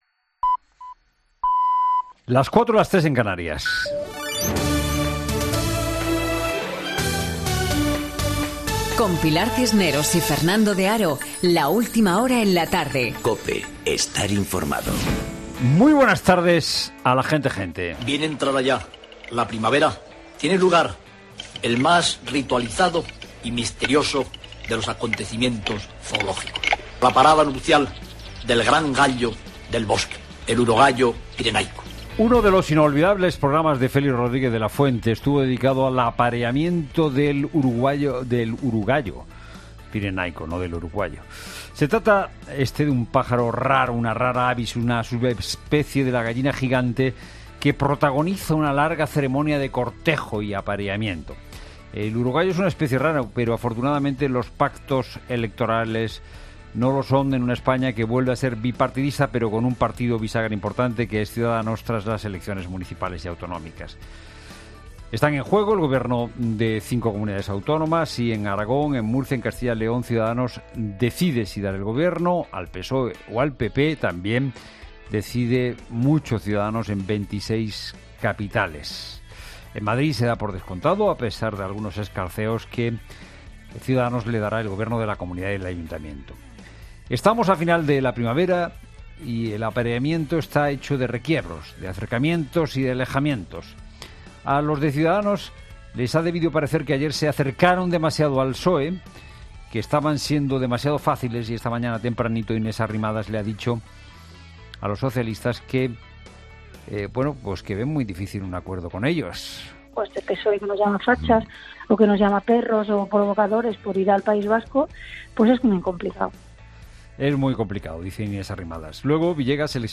Escucha el Monólogo de las 16h. de Fernando De Haro del 28 de mayo de 2019